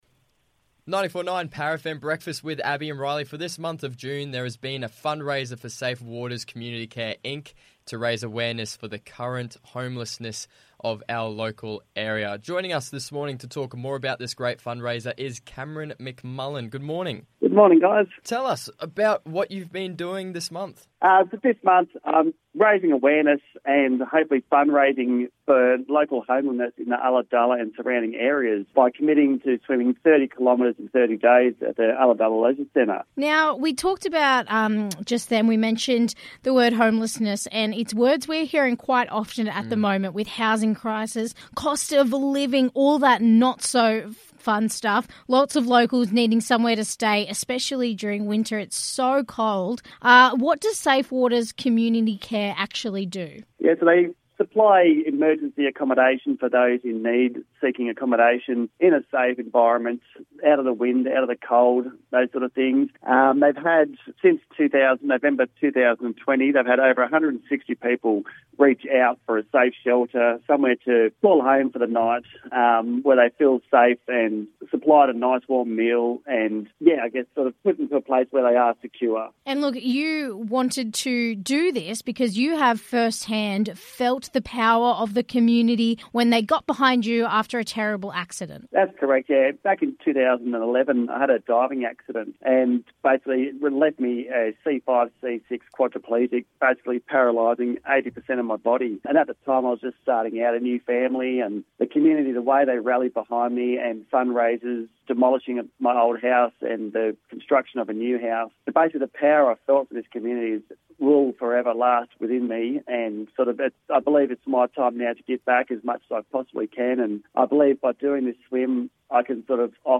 Listen to their full chat from this morning!